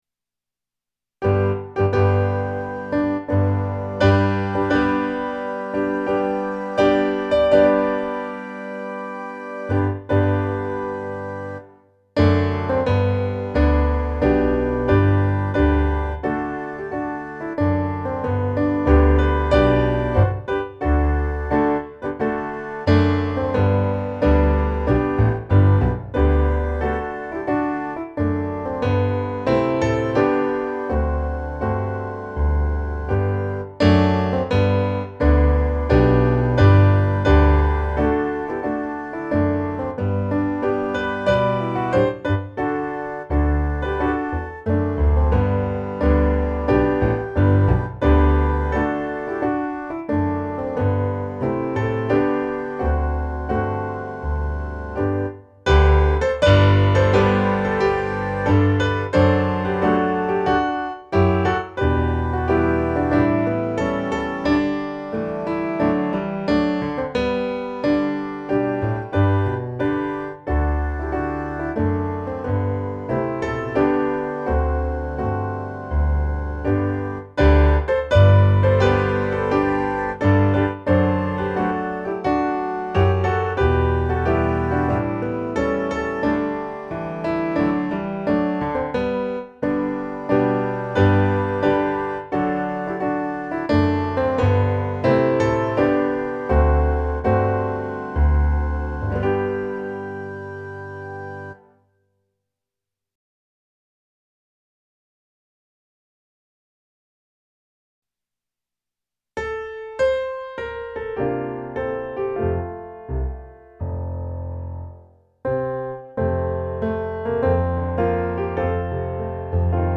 Hosianna, Davids son - musikbakgrund
Musikbakgrund Psalm